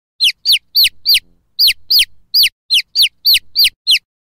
Rainbow chicks Chick sound sound effects free download
Rainbow chicks -Chick sound - Cute baby chicken